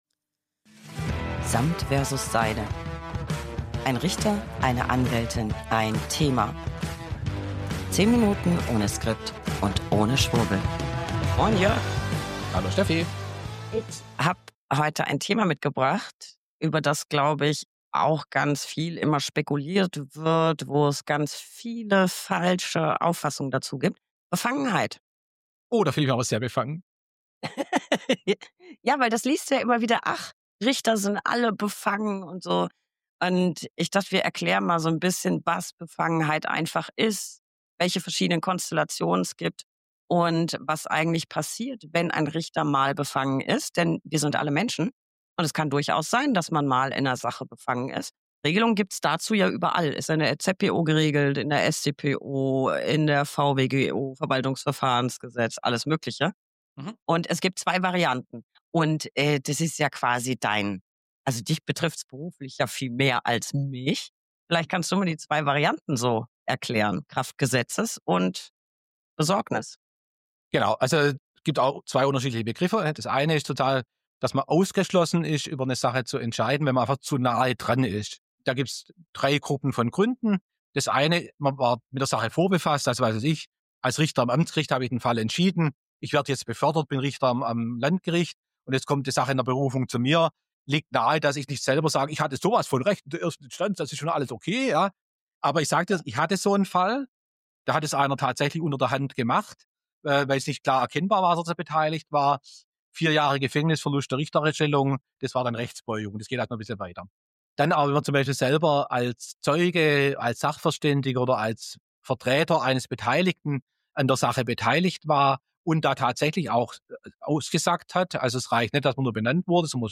Beschreibung vor 8 Monaten 1 Anwältin + 1 Richter + 1 Thema. 10 Minuten ohne Skript und ohne Schwurbel.